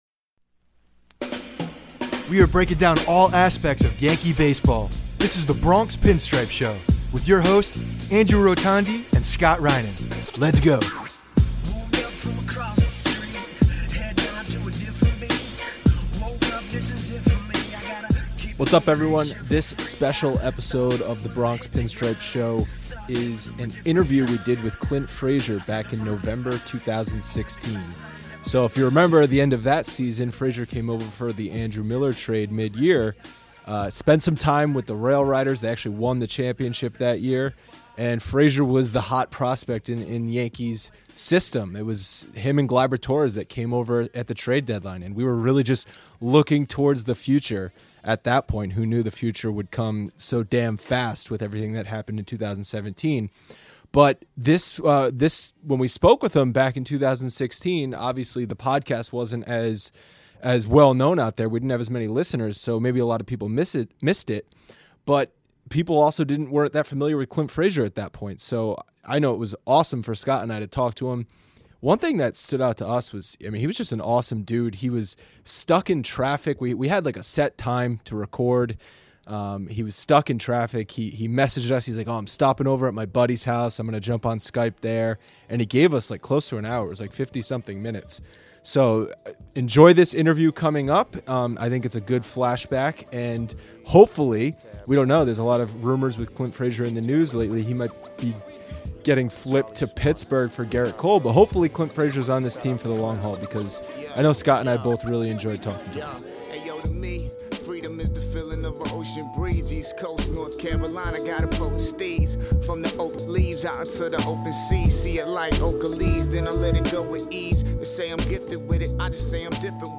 Flashback: Clint Frazier Interview - The Bronx Pinstripes Show